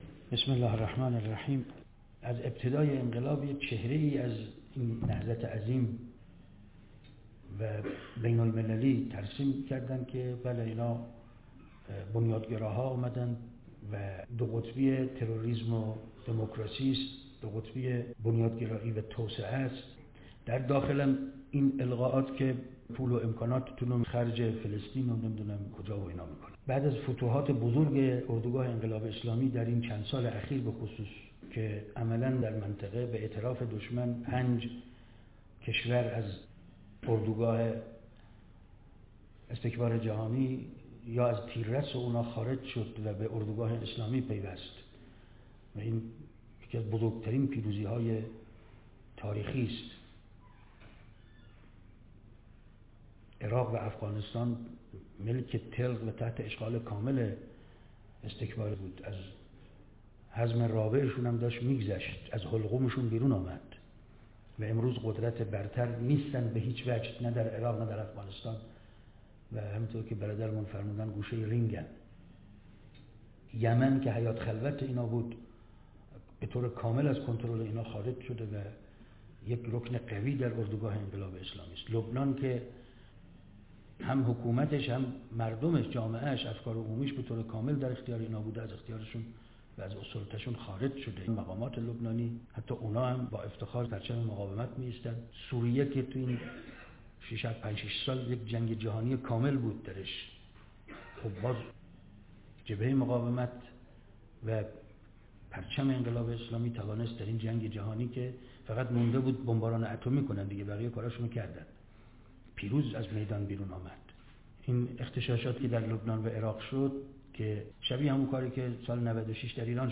نشست (ما بی‌طرف نیستیم؛ نگاهی به منطق دفاع از «غزه و لبنان») _ ۱۳۹۸